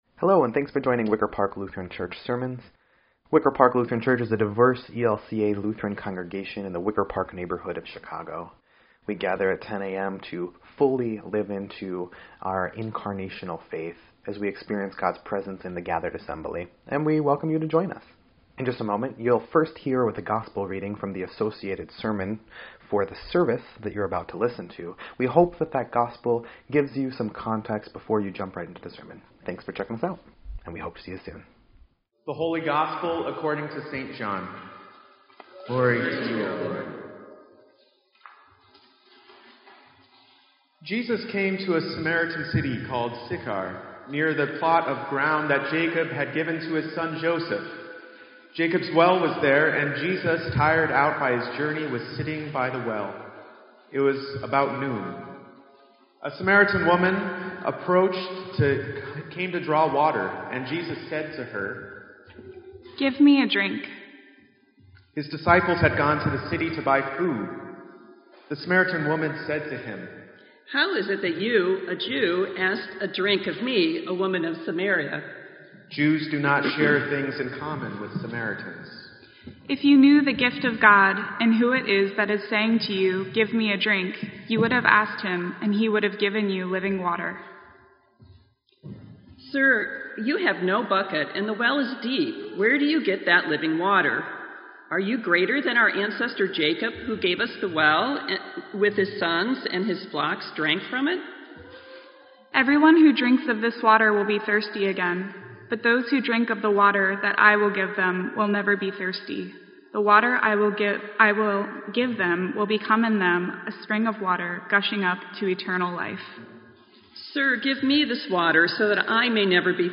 Sermon_3_19_17_EDIT.mp3